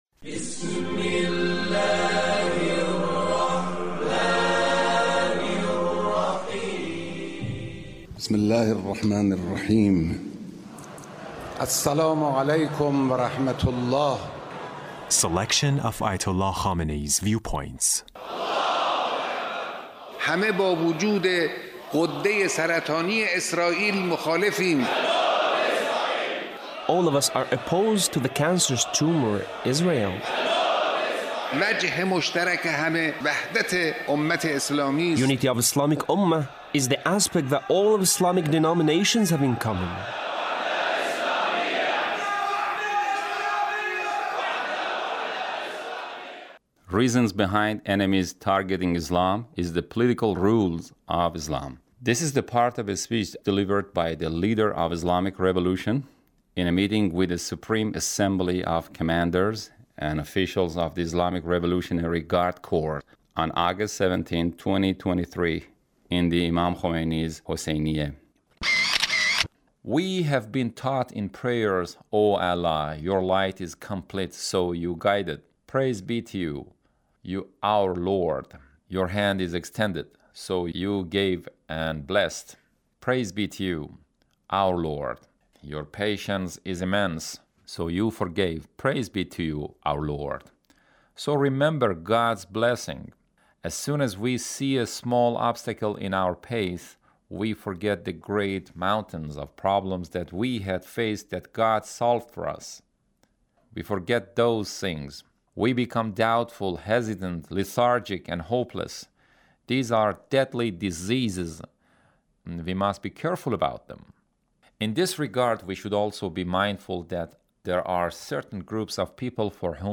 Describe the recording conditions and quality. Leader's Speech in a meeting with Revolution in a meeting with the Supreme Assembly of Commanders and Officials of the Islamic Revolutionary Guard Corps (...